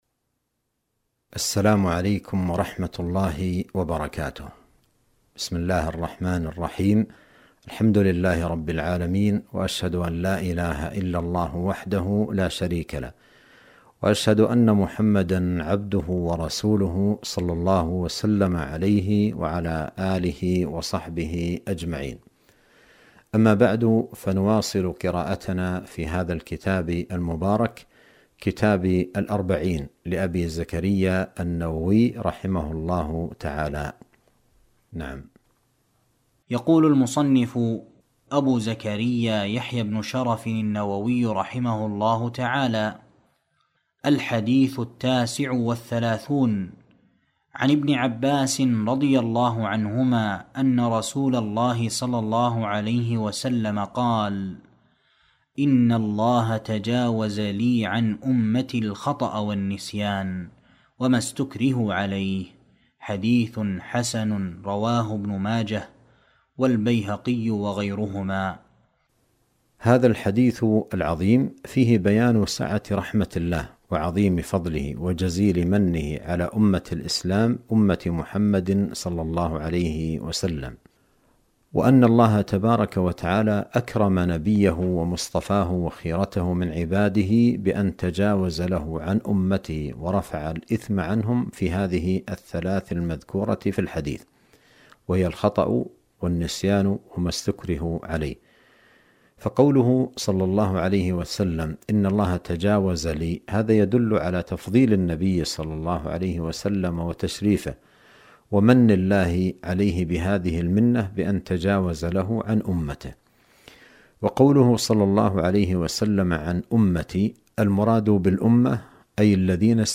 درس في قناة السنة النبوية بالمدينة النبوية